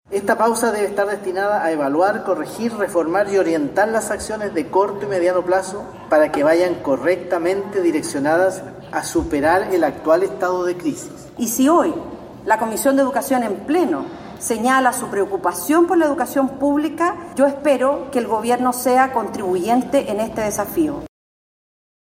Así lo indicó el presidente de esa instancia, el senador de Renovación Nacional, José García Ruminot, y la senadora de la Democracia Cristiana por Atacama, Yasna Provoste.